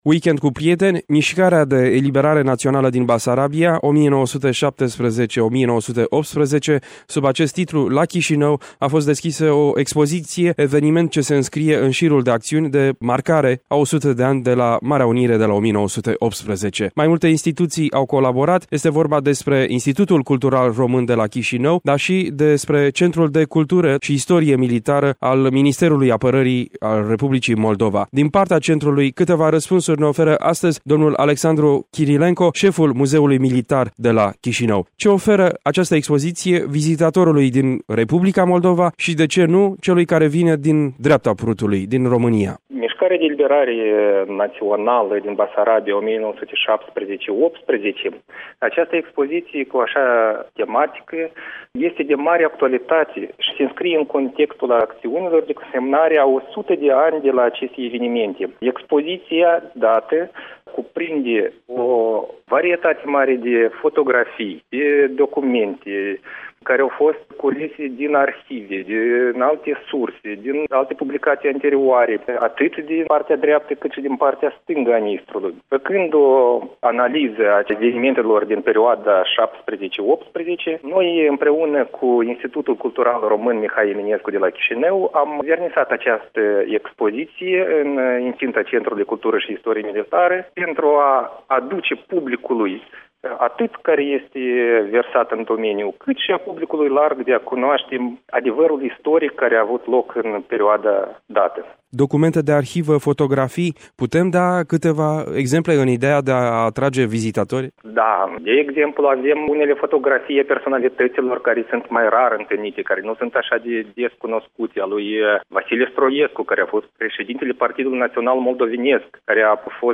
Un dialog realizat